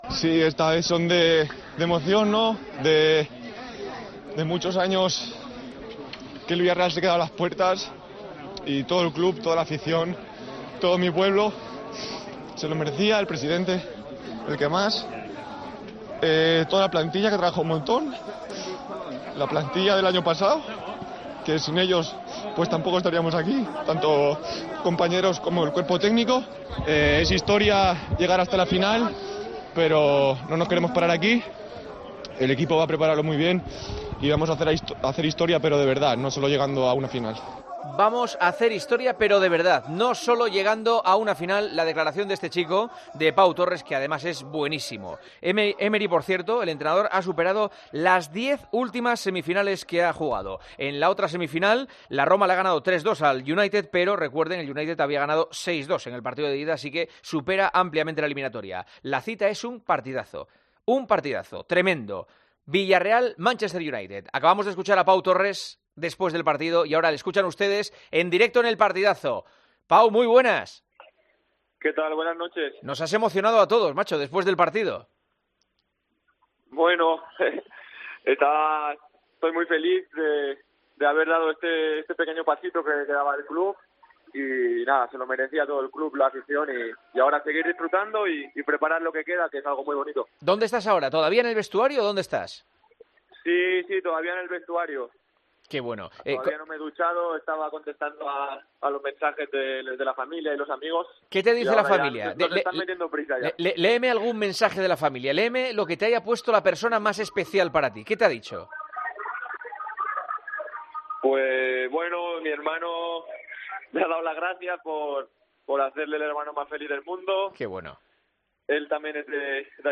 AUDIO: Felicitamos al central del Villarreal por la histórica clasificación para la final de la Europa League y le saluda Marcos Senna en antena.